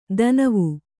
♪ danavu